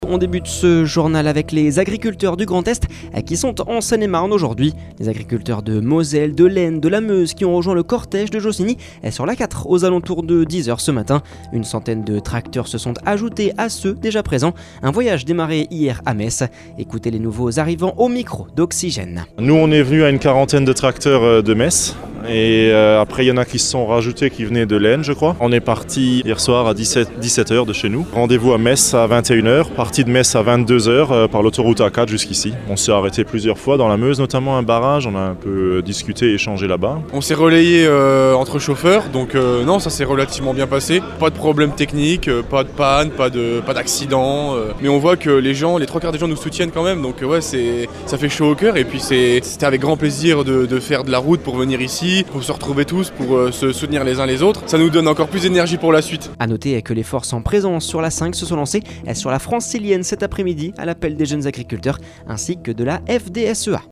Un voyage démarré hier à Metz, écoutez les nouveaux arrivants au micro d’Oxygène…